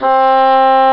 Dulcian Sound Effect
dulcian.mp3